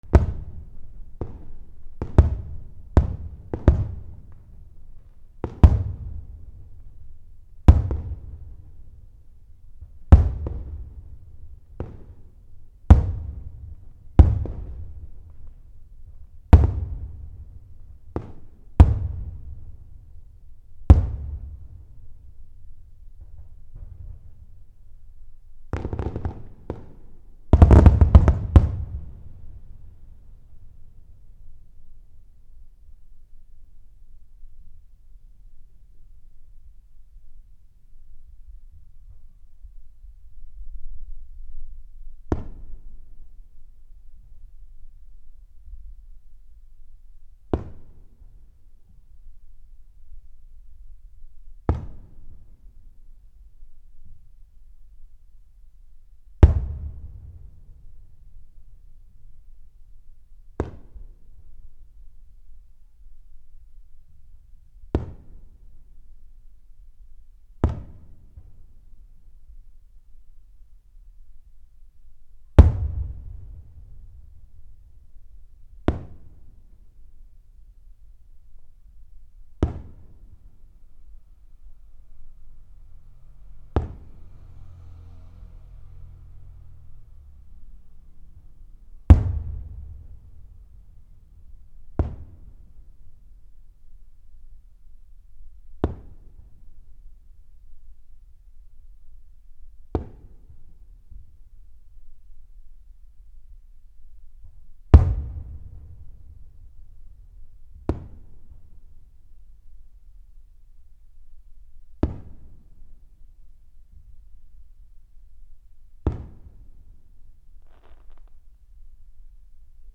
/ C｜環境音(人工) / C-45 ｜花火
花火 第38回やまとの夏まつり7
mix(打ち上げ場所より300m程度)NT4 MKH416 ATM25